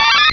Cri de Pichu dans Pokémon Rubis et Saphir.